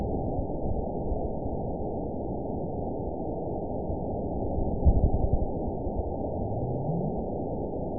event 920049 date 02/16/24 time 01:02:21 GMT (1 year, 4 months ago) score 9.48 location TSS-AB05 detected by nrw target species NRW annotations +NRW Spectrogram: Frequency (kHz) vs. Time (s) audio not available .wav